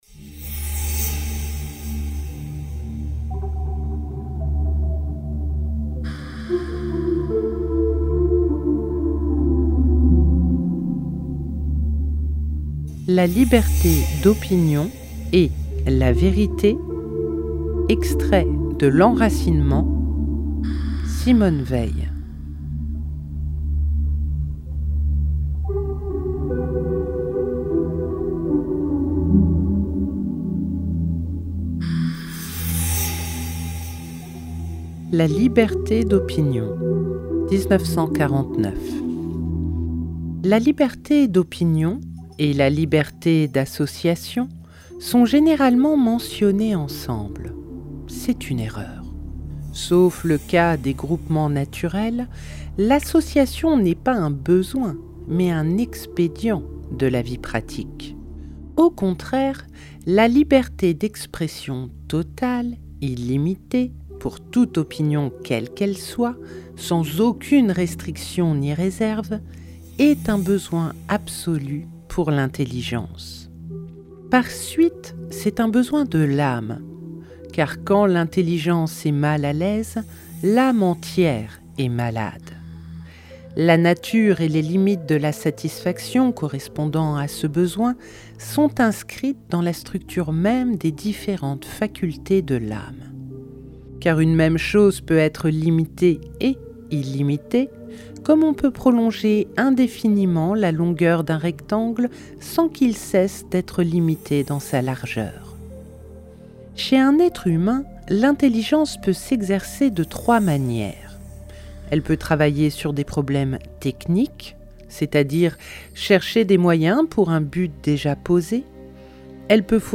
🎧 La liberté d’opinion & Vérité – Simone Weil - Radiobook